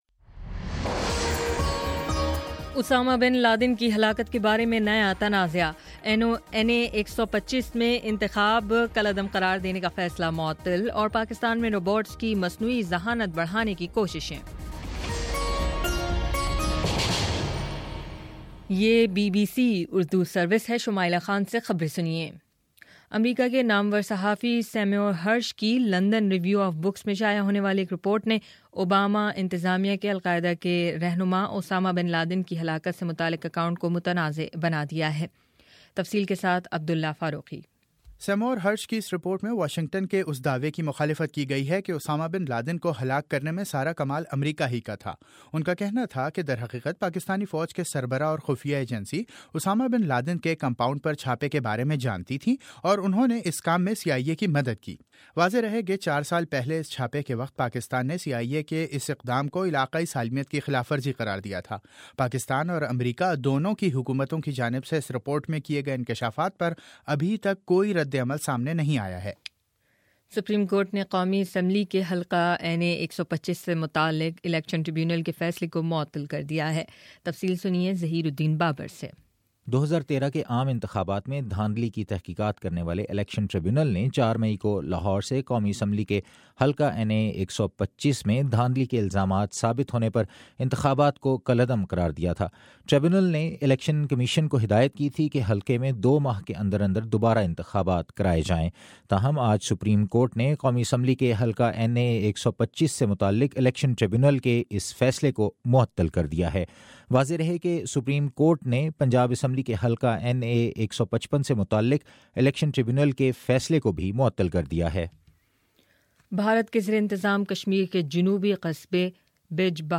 مئی 11: شام سات بجے کا نیوز بُلیٹن